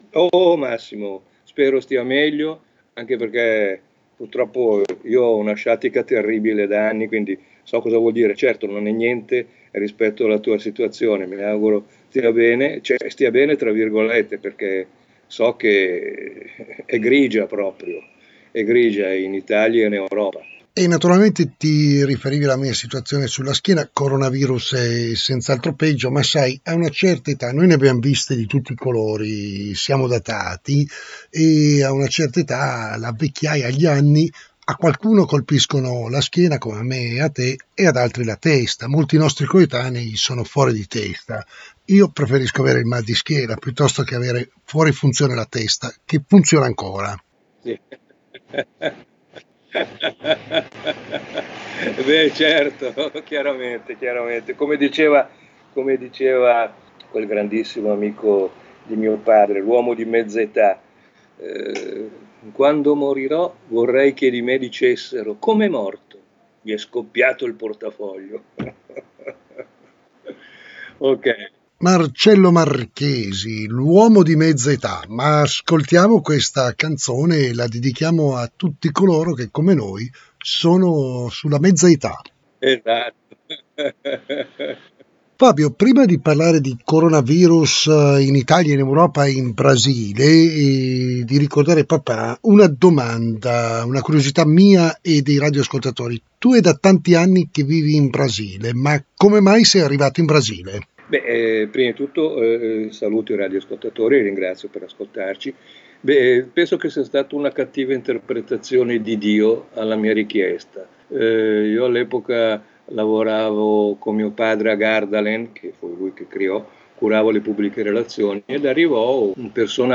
Da lunedì 6 aprile alle ore 17,30 su Radio Free Live in onda la puntata pre pasquale della trasmissione radiofonica L'angolo della scuola.